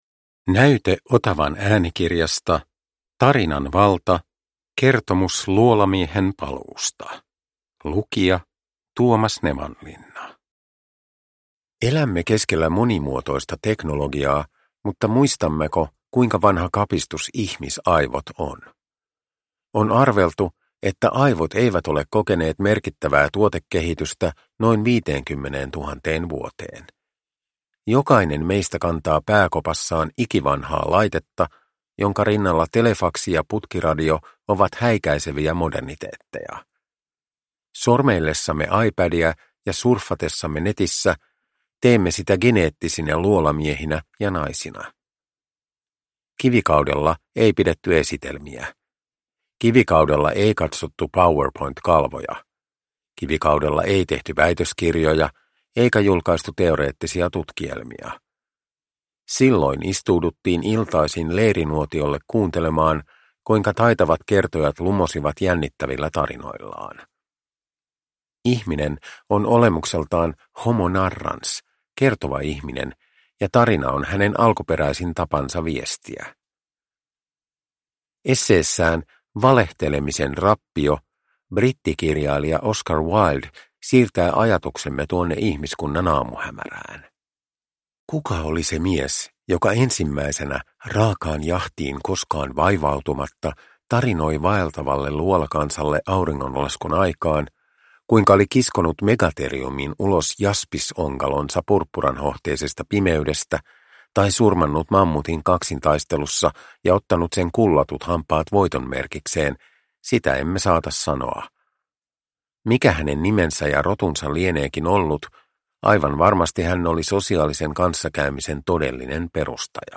Produkttyp: Digitala böcker
Uppläsare: Tuomas Nevanlinna